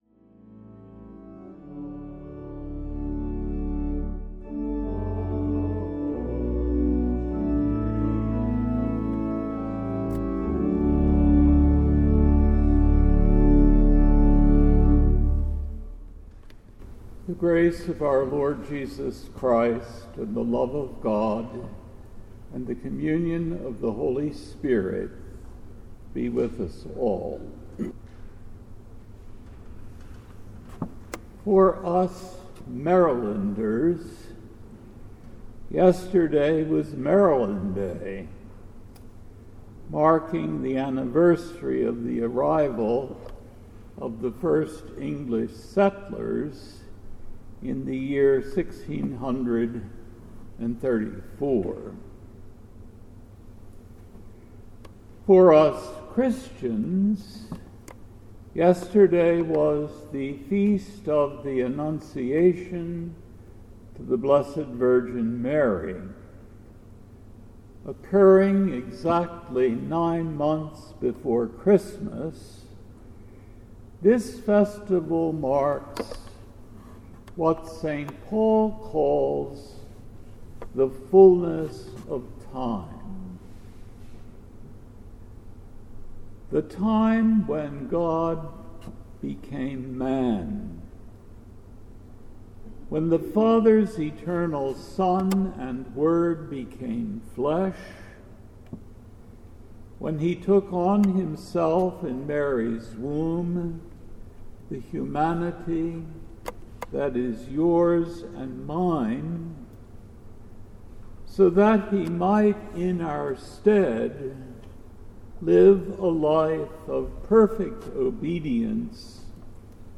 This podcast features a weekly sermon